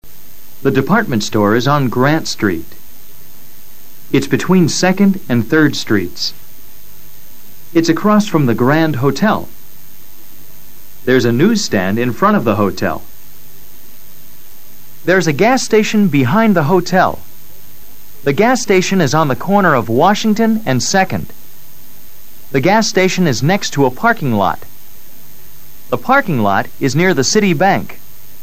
Escucha al profesor leyendo oraciones con PREPOSICIONES DE LUGAR.